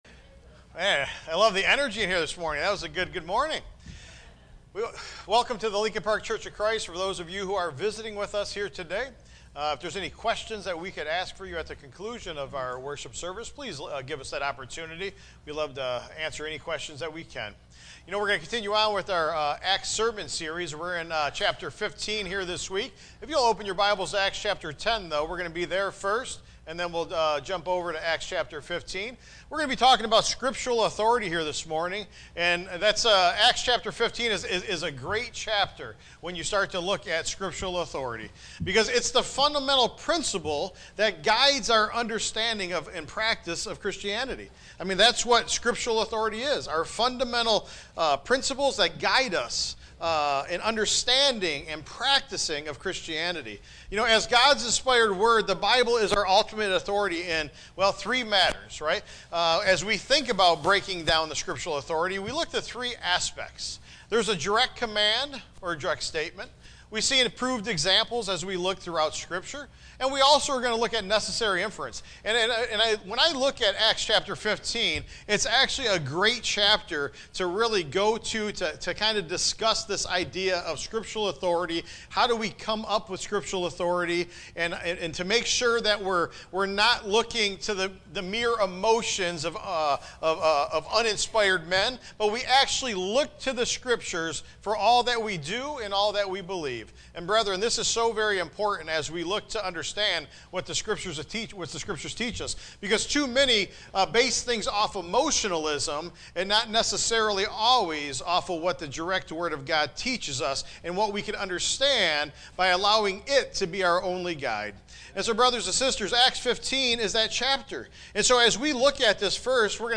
Where do we get scriptural authority? A Direct Command or Statement Approved Example Necessary Inference Tagged with sermon